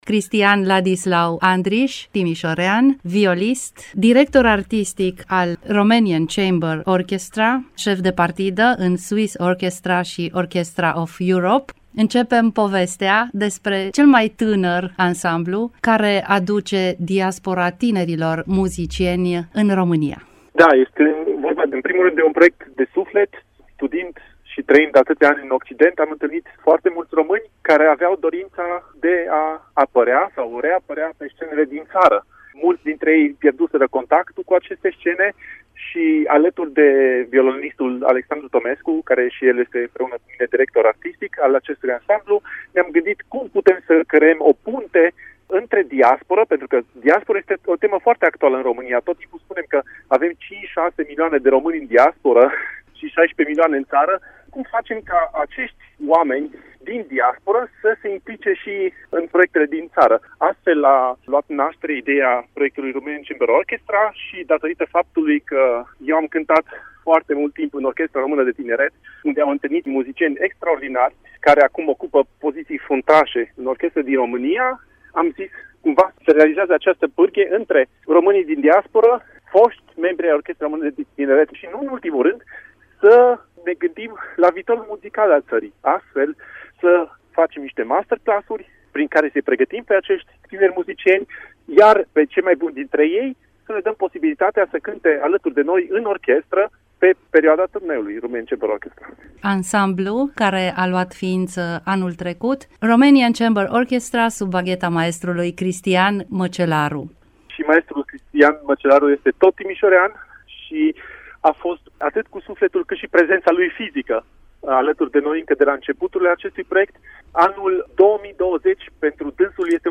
Despre numitorul special Romanian Chamber Orchestra şi proiectele 2020, un dialog